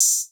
Open Hats
{OpenHat} TTLN2.wav